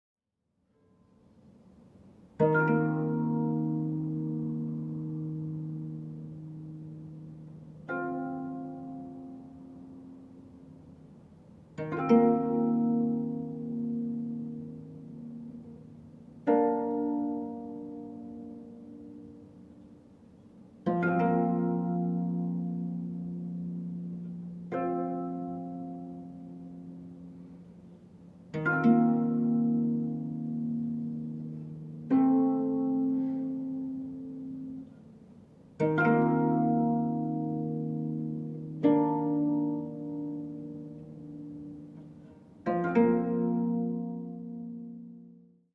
十七絃箏と、カリンバ／ハーモニウム／女声による音の綴り。
中低音の響きに豊かな印象を受ける十七絃箏が持つシンプルさと奥深さ。”
(17-strings koto)